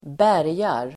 Uttal: [²b'är:jar]